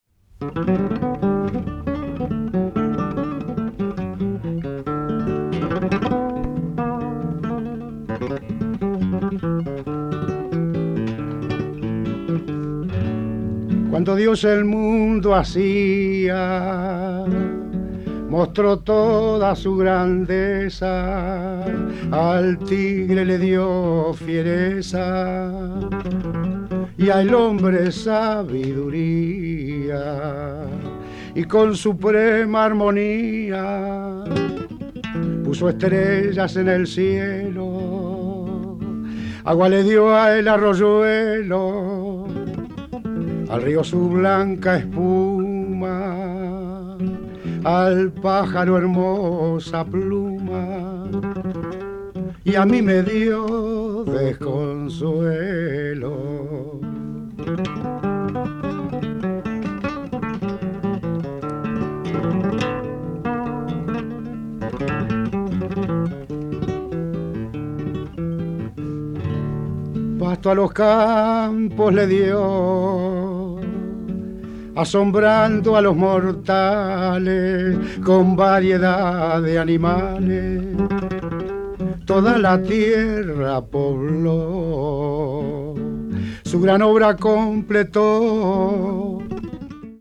華麗なギター捌きを堪能できる曲や、クラシカルなピアノ、濃厚な宗教歌など非常に面白いですよ！